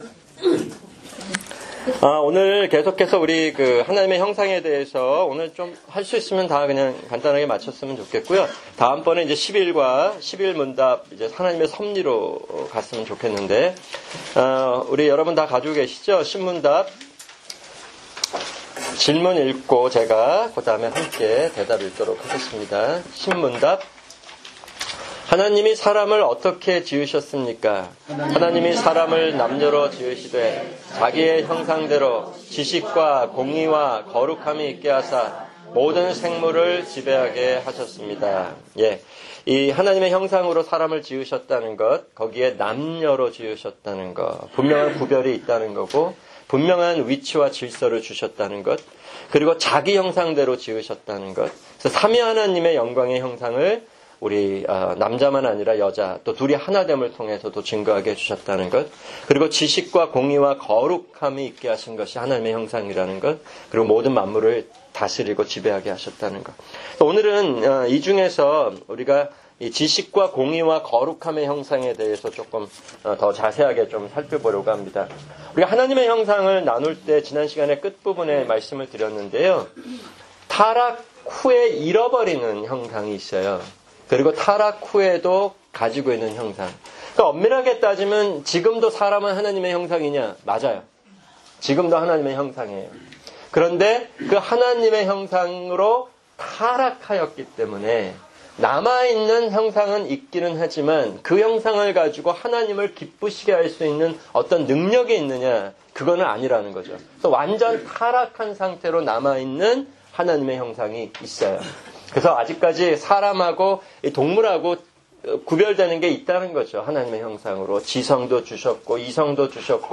[주일 성경공부] 소요리문답 10문답(4)